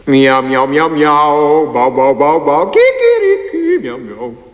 miaomiao.wav